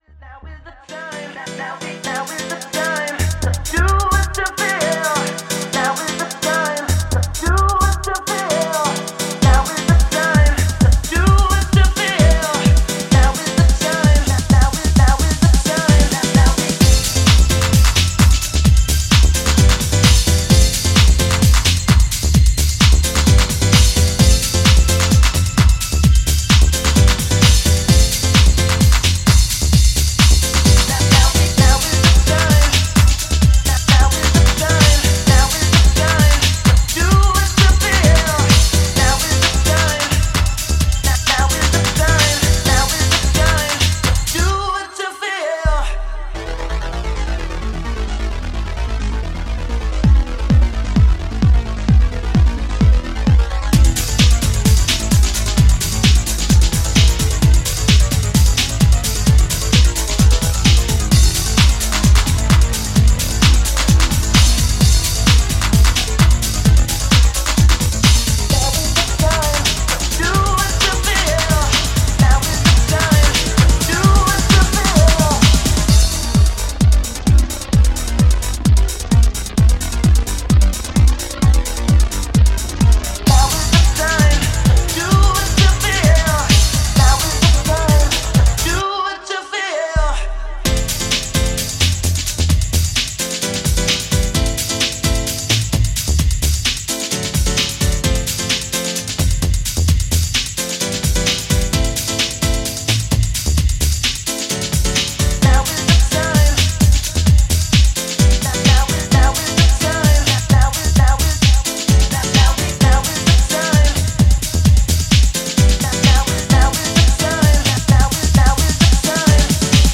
classic rave sounds